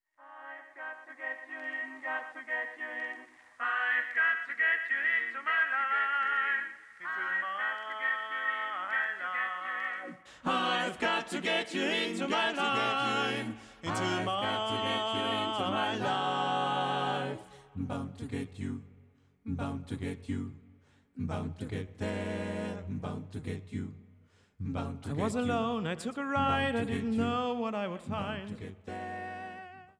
Eine CD mit Chansons und Popsongs ist entstanden.